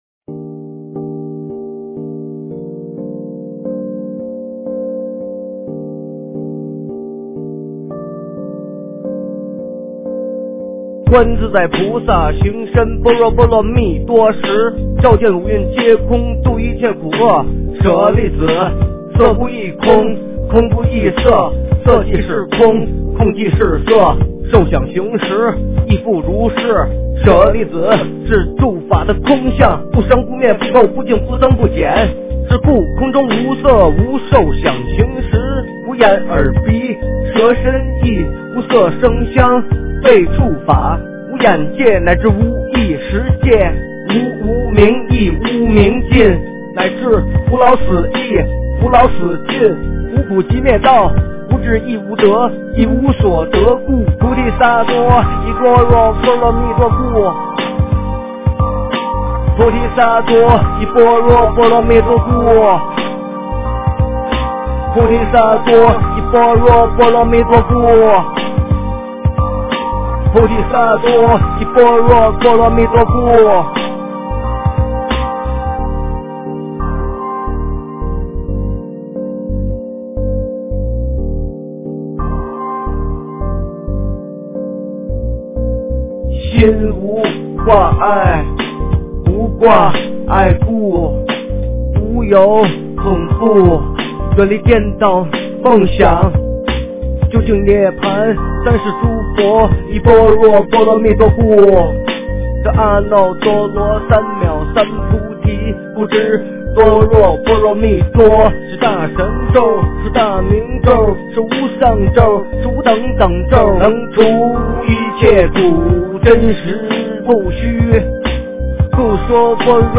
诵经
佛音 诵经 佛教音乐 返回列表 上一篇： 佛说无量寿经-下 下一篇： 念阿弥陀佛歌 相关文章 空城退敌卧龙吟-古筝--未知 空城退敌卧龙吟-古筝--未知...